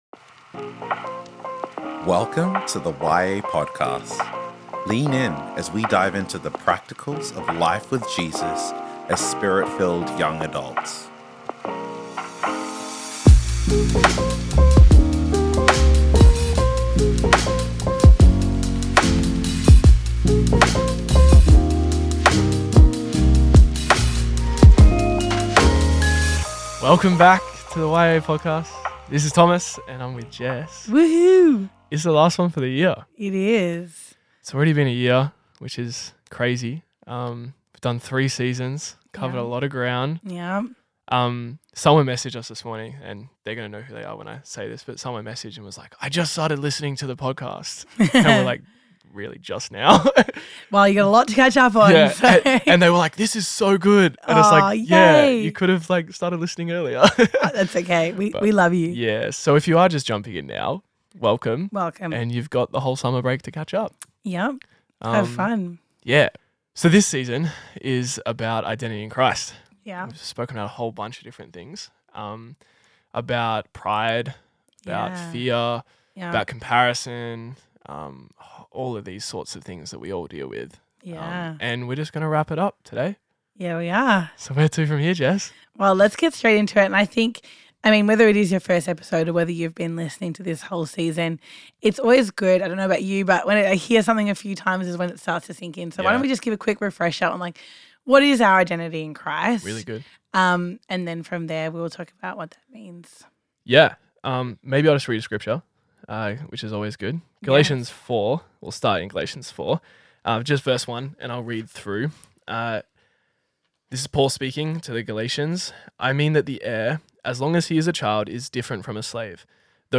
Conversations: Identity Finale